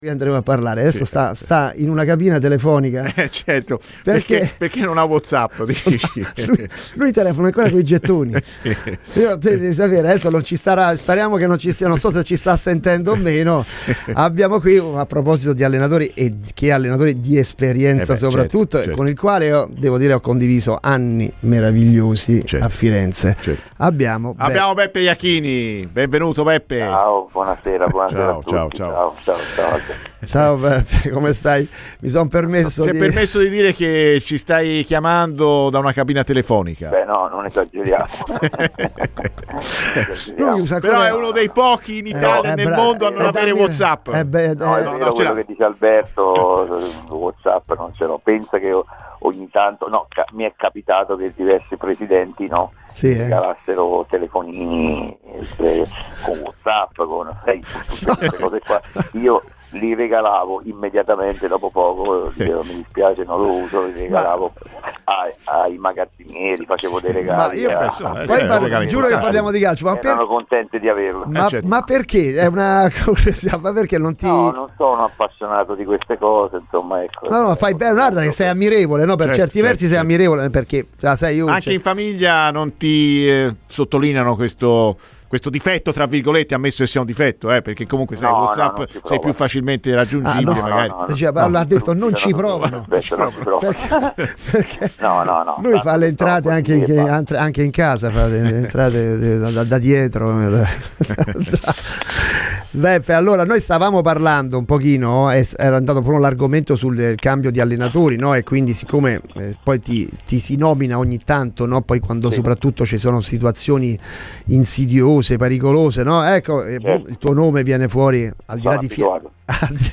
Ascolta il resto dell’intervista nel nostro podcast!